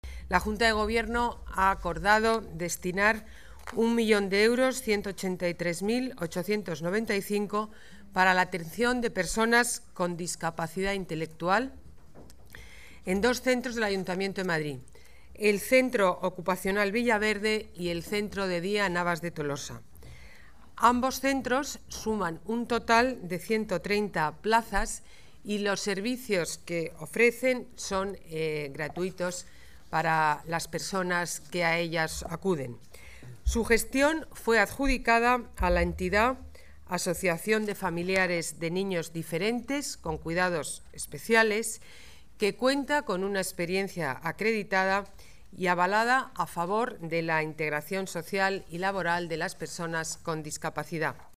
Nueva ventana:Declaraciones de la alcaldesa Ana Botella: Atención Personas con Discapacidad